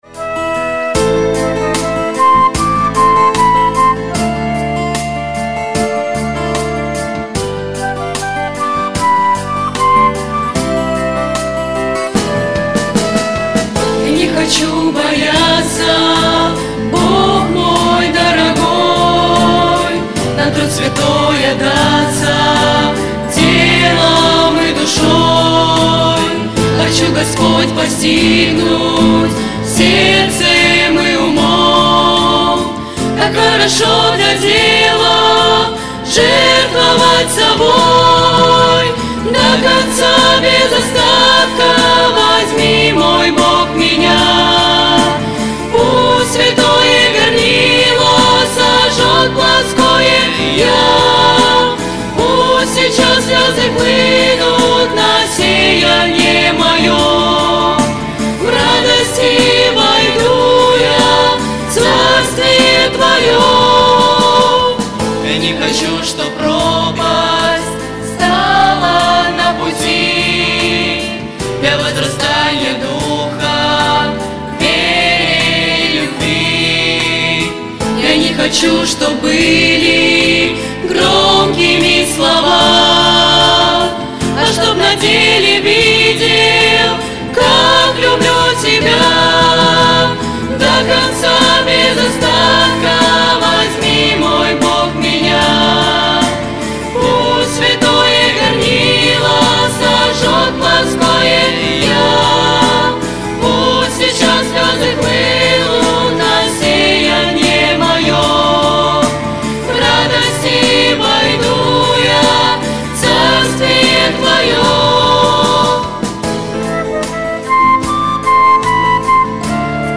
Богослужение 11.02.2024
До конца, без остатка - Благая весть (Пение)[